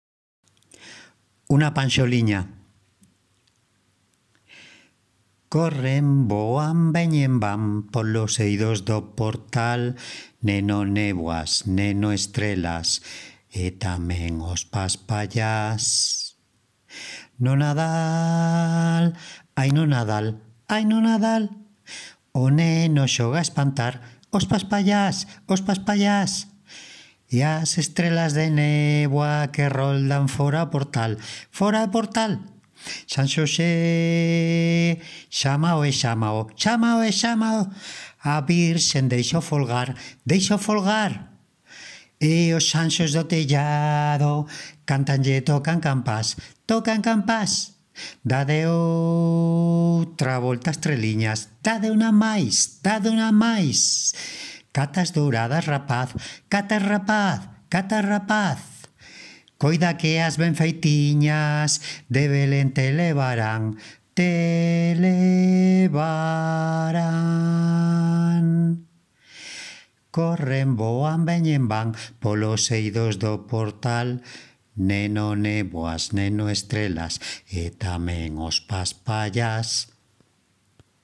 Audio da panxoliña
Grabadora básica de Android